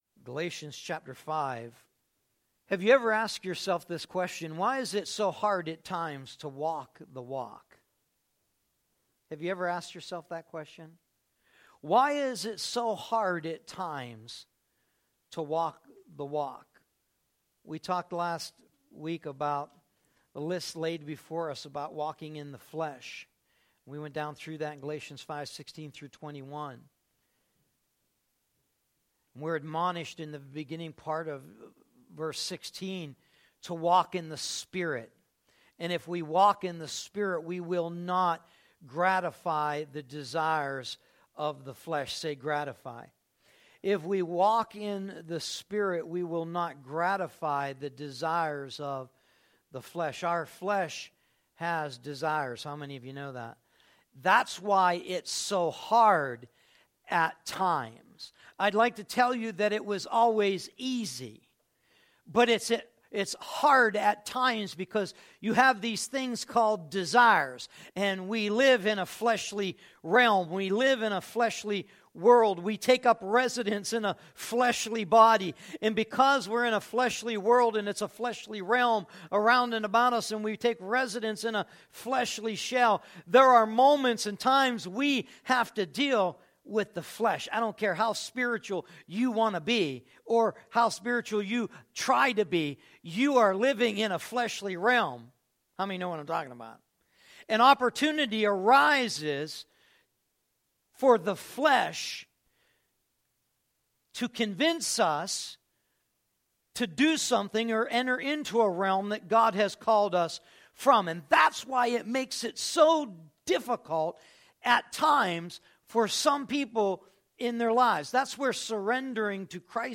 “180509_0668.mp3” from TASCAM DR-05.